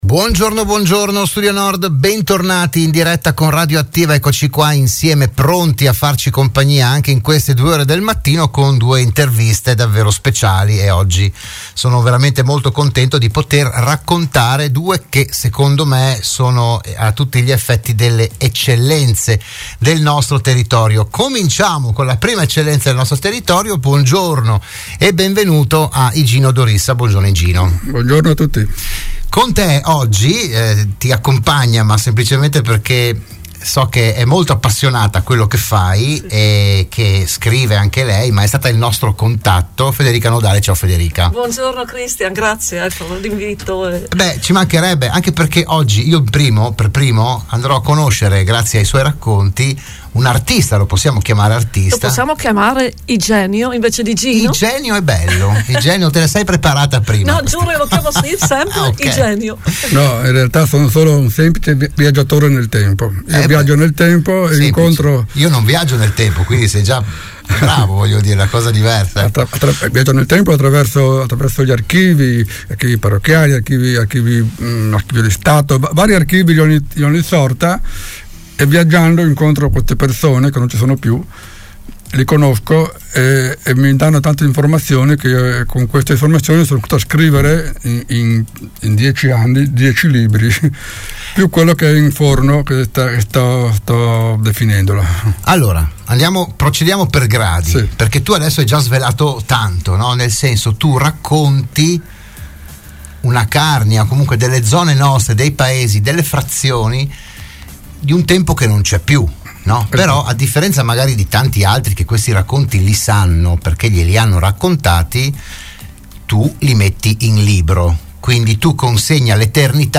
Ospite oggi della trasmissione del mattino "RadioAttiva"